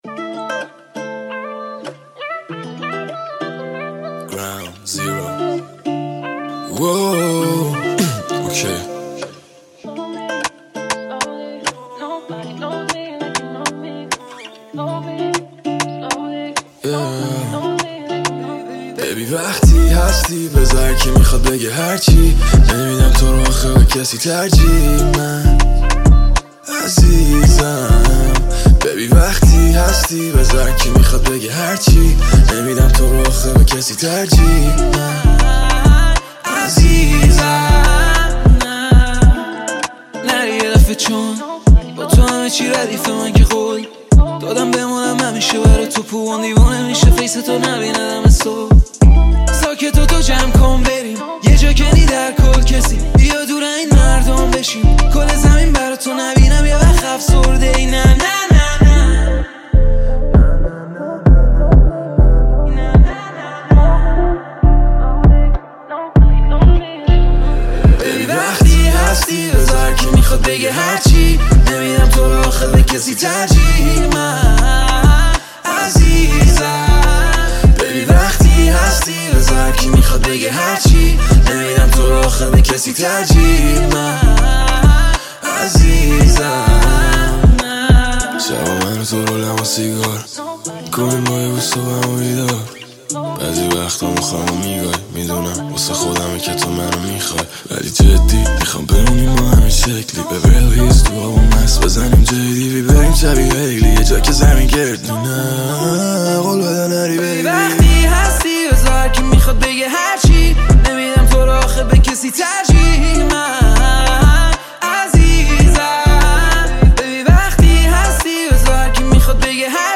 hiphop & rap